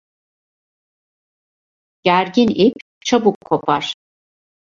Pronúnciase como (IPA)
/ip/